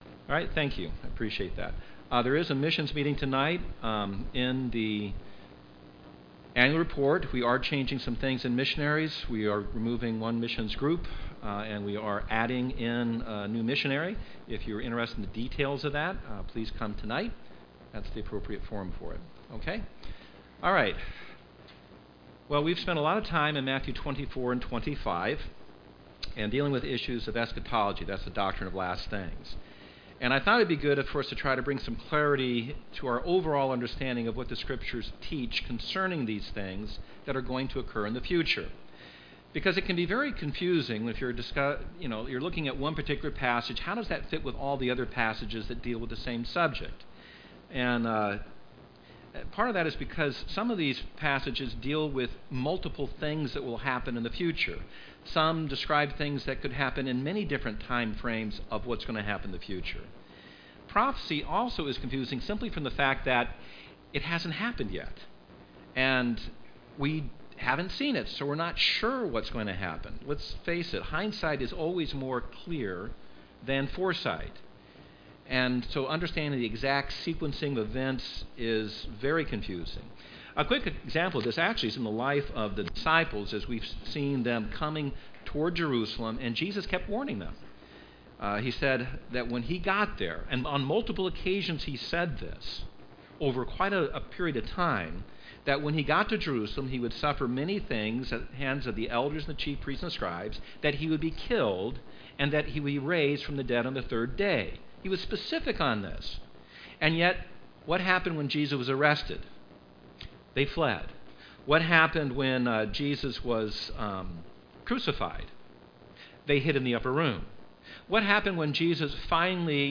Sermons 2019